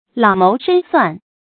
lǎo móu shēn suàn
老谋深算发音
成语注音 ㄌㄠˇ ㄇㄡˊ ㄕㄣ ㄙㄨㄢˋ
成语正音 谋，不能读作“máo”。